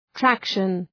traction.mp3